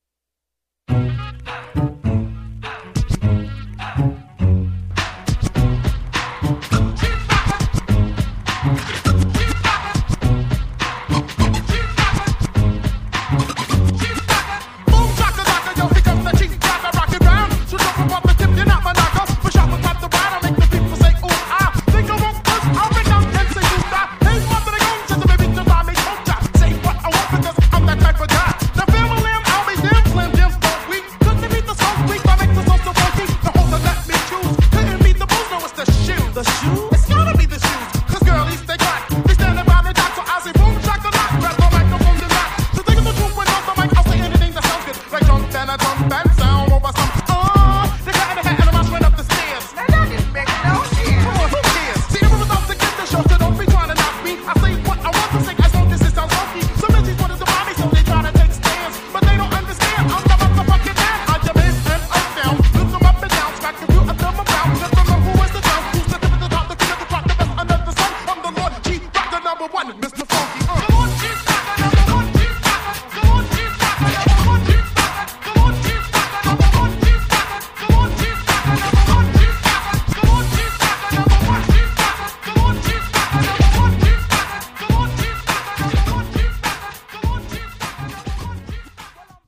103 bpm
Dirty Version